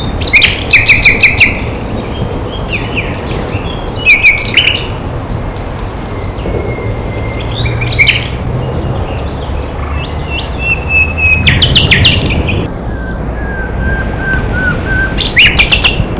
Dai primi di Aprile fino alla fine di Giugno è facile sentire il canto sonoro e bellissimo dell'Usignuolo provenire dal fitto sottobosco della macchia. Particolarmente suggestivo diventa nei mesi di Maggio e Giugno, quando questo uccello canta anche in piena notte.
usignolo.wav